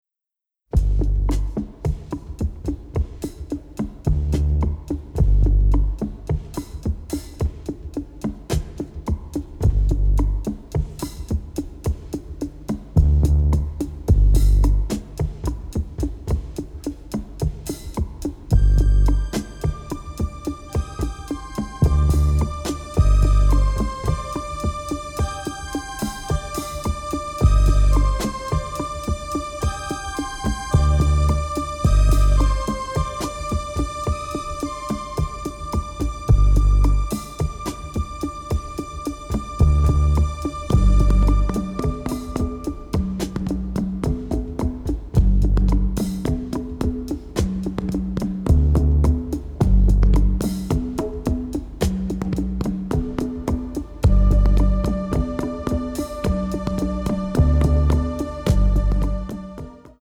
ethnic-flavored eclectic underscoring